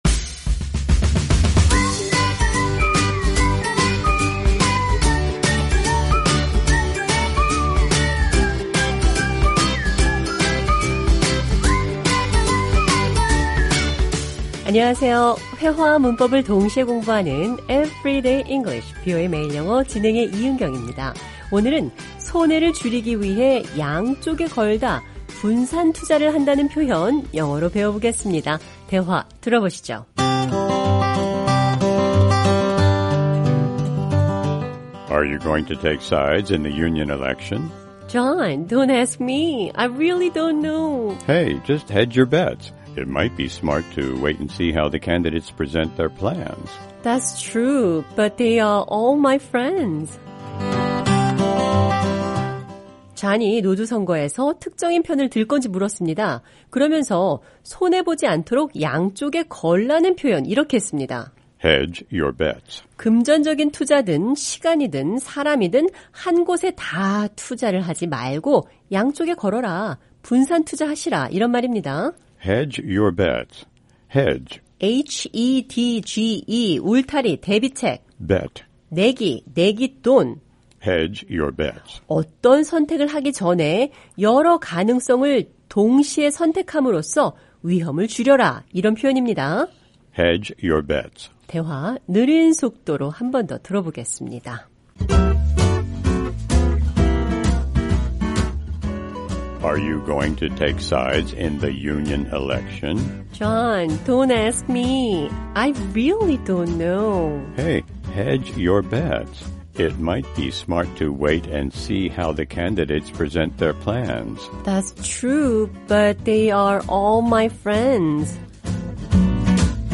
오늘은 손해를 줄이기 위해, 양쪽에 걸다, 분산투자를 한다는 표현 영어로 배워보겠습니다. 대화 들어보시죠.